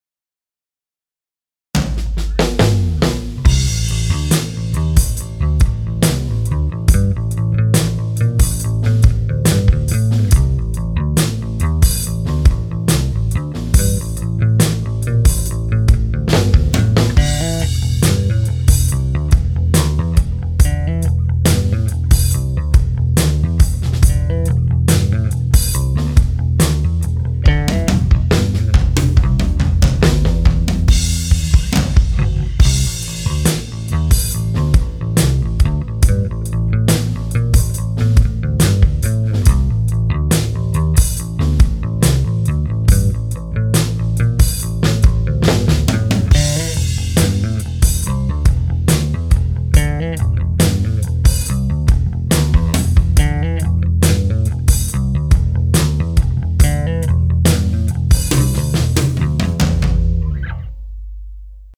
Turns out, the BDi DOES have some unusual distortion in the low range.
Wouldn't mind some feedback on this mix Attachments BDi BASS.mp3 BDi BASS.mp3 1.9 MB